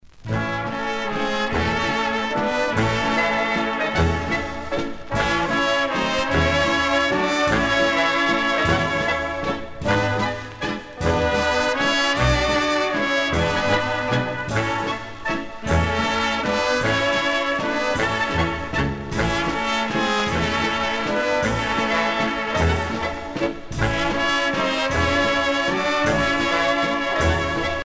danse : valse lente
Pièce musicale éditée